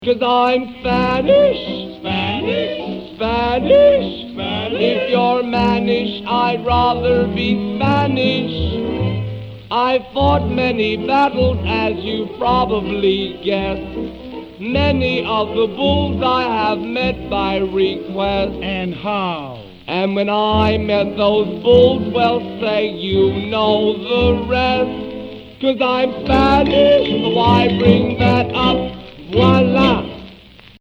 10" 78 rpm